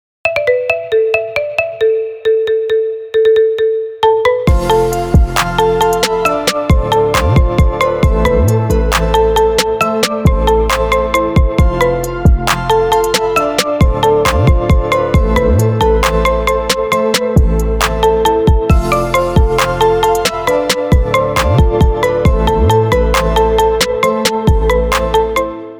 • Качество: 320, Stereo
без слов
качающие
Ремикс в Apple стиле